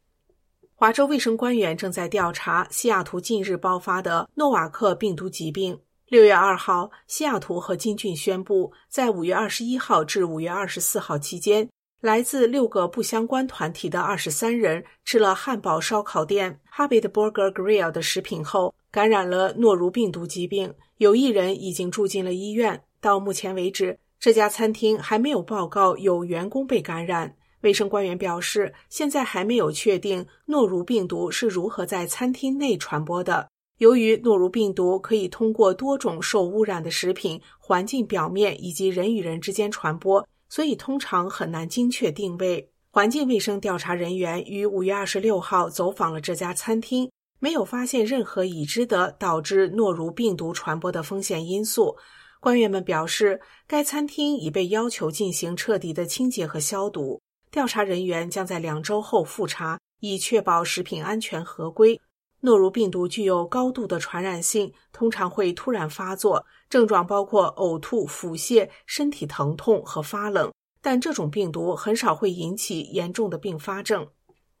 新聞廣播